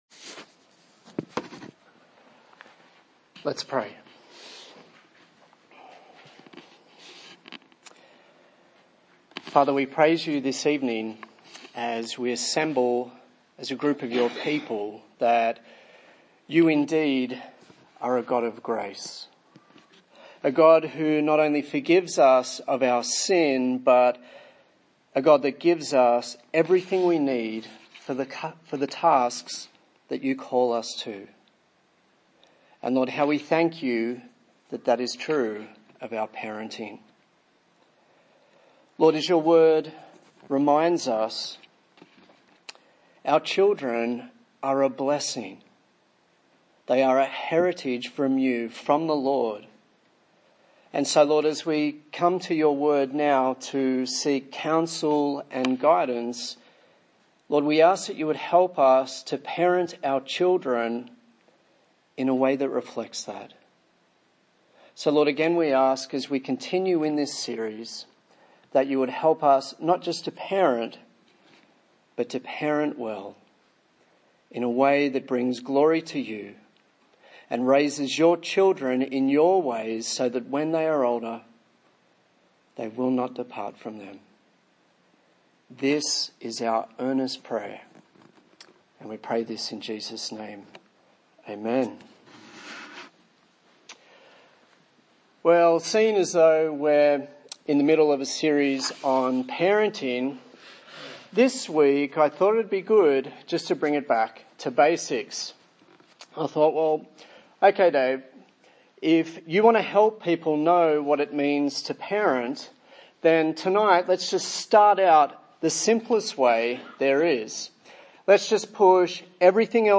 A sermon in the series on Parenting - Honour & Obey